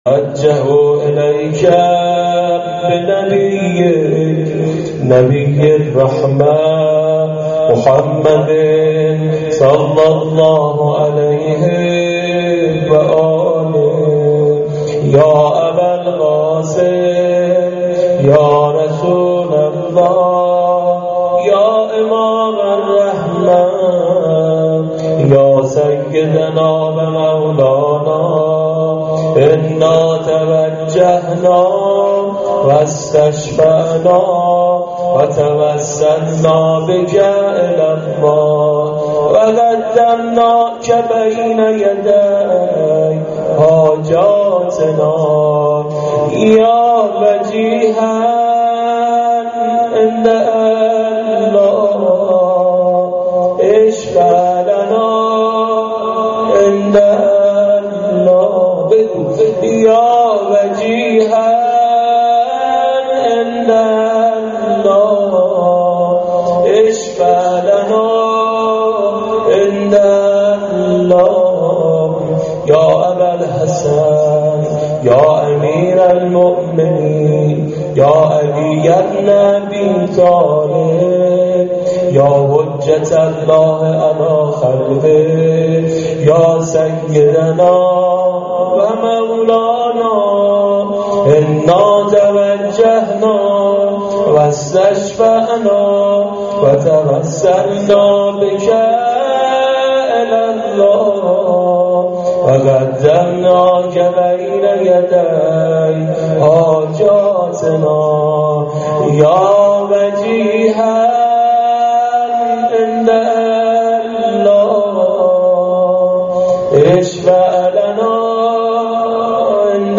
قسمت اول دعای توسل و روضه حضرت زهرا.mp3